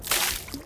Play, download and share Gas Fail original sound button!!!!
gas_spill_01.mp3